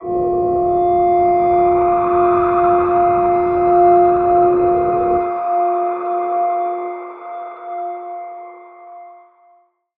G_Crystal-F5-f.wav